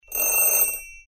clock.mp3